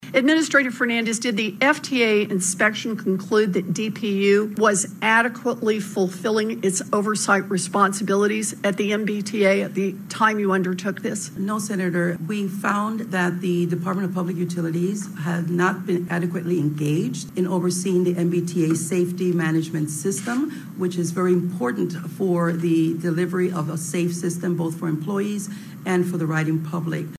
A Field Hearing for the U.S. Senate Committee on Banking, Housing, and Urban Affairs Subcommittee on Economic Policy was held in Boston on Friday by Senators Elizabeth Warren and Ed Markey to discuss the current state of the MBTA following a report by the Federal Transit Administration regarding 53 problem areas.
Senator Elizabeth Warren spoke with Administrator Nuria Fernandez, of the Federal Transit Administration.